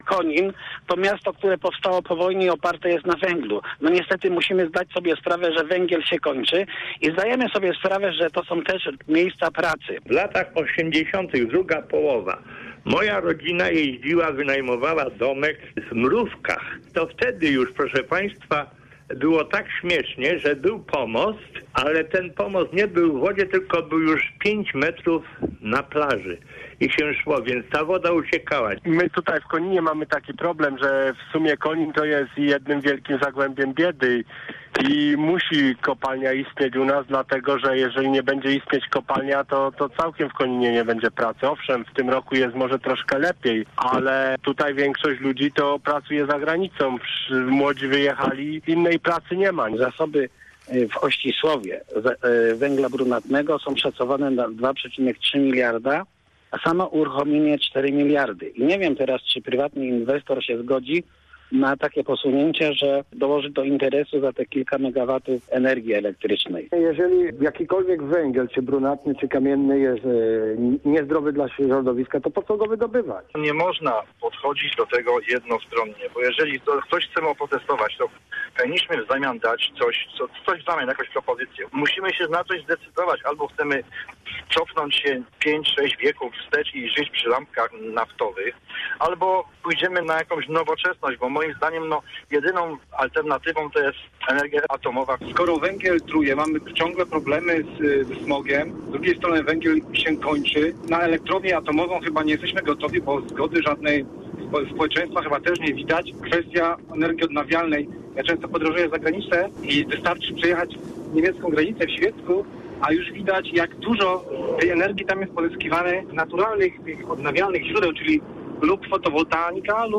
n4cnedo23x3gxo5_skrot-dyskusji-o-odkrywce-oscislowo.mp3